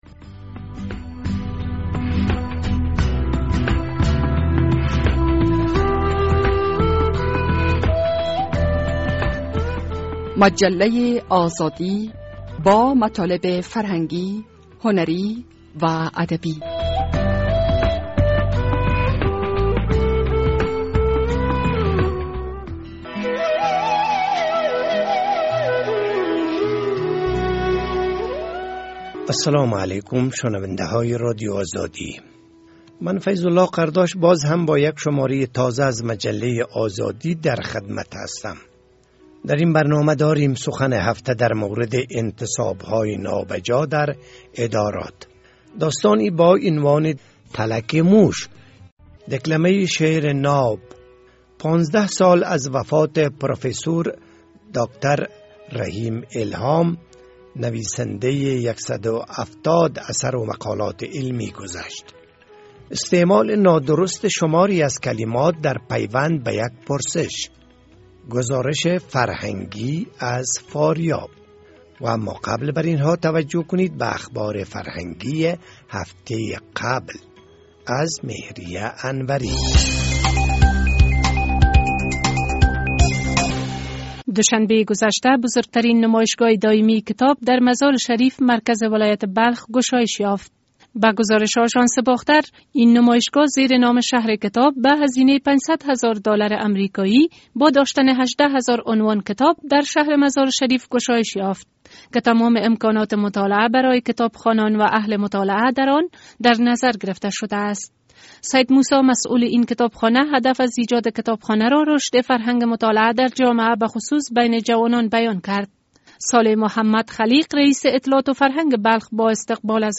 سخن هفته در مورد انتصاب‌های نابجا در ادارات، داستانی با عنوان (دیدگاه خانواده)، دکلمه شعرناب ...